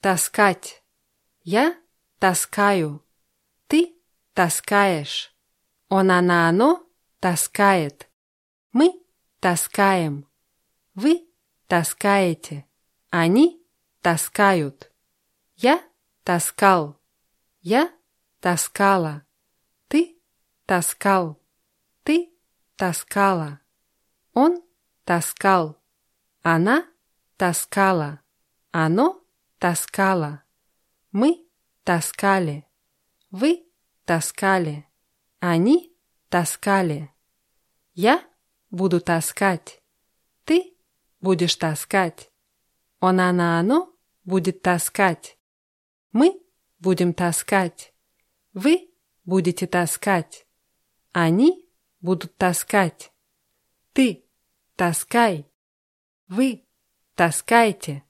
таскать [taßkátʲ]